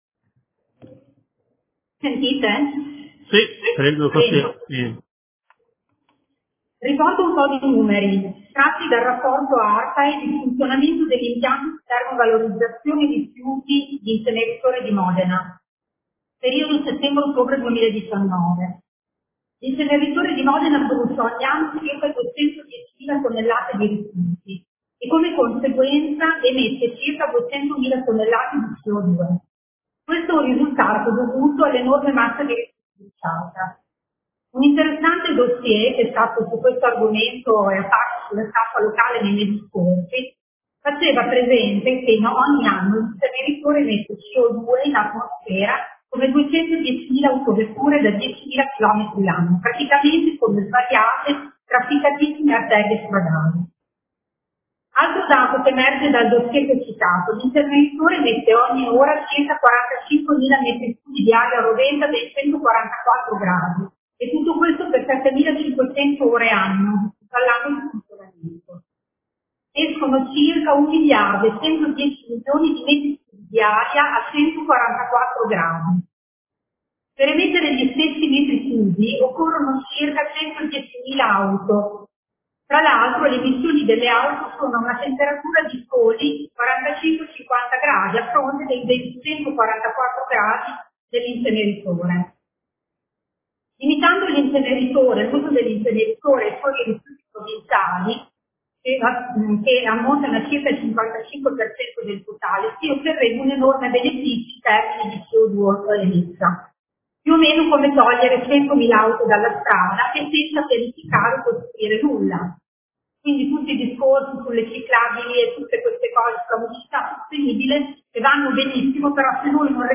Elisa Rossini — Sito Audio Consiglio Comunale